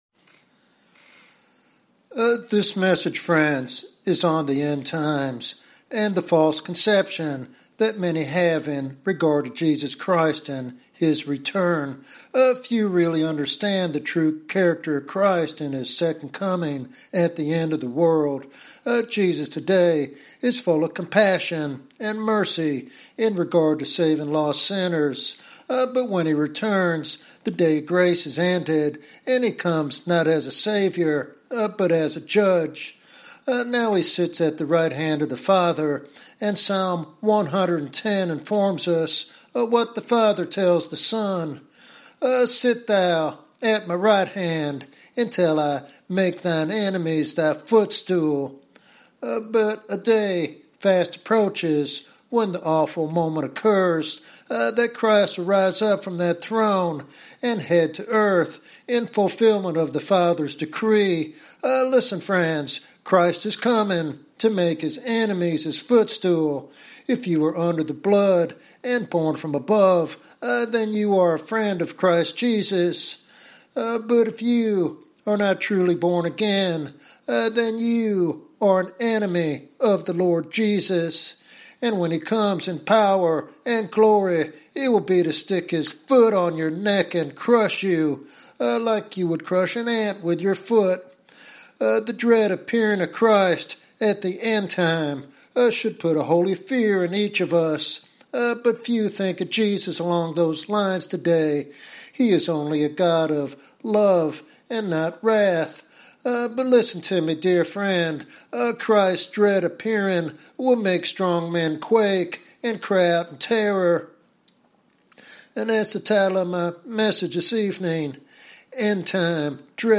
In this prophetic sermon